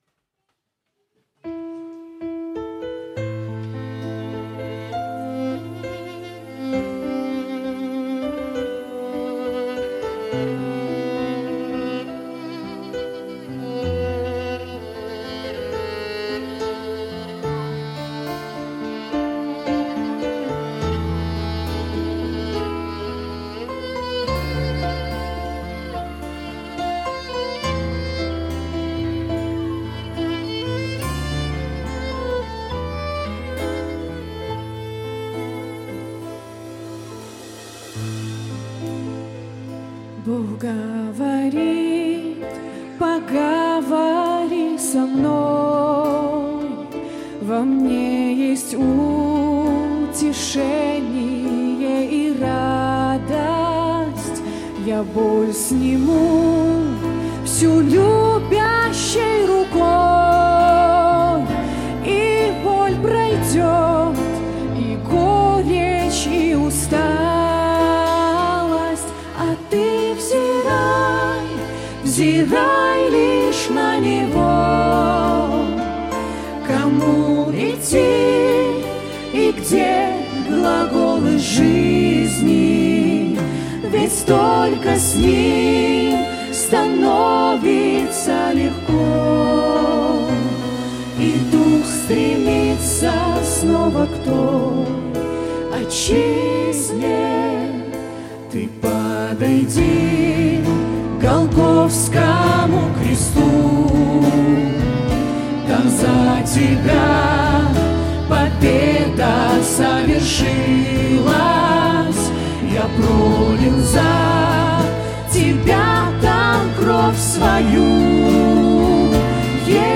Музыкальная группа "Осанна"
скрипка, гитара, вокал
клавиши, вокал